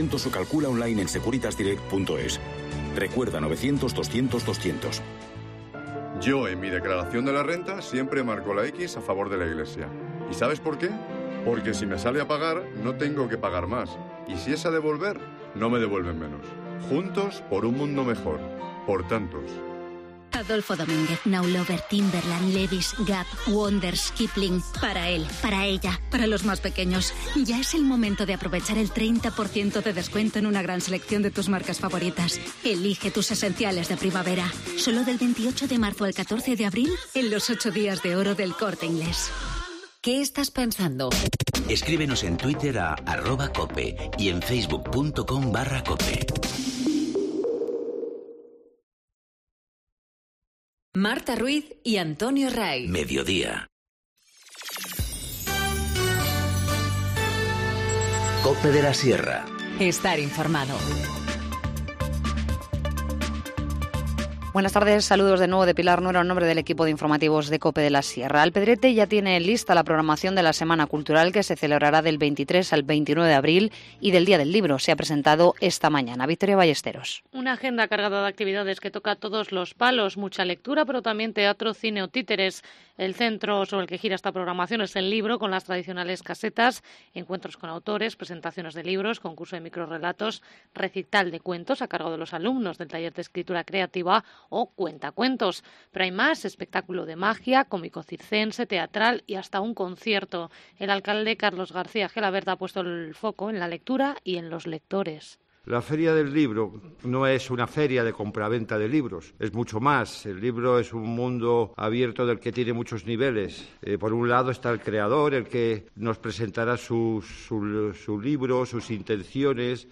Informativo Mediodía 3 abril 14:50h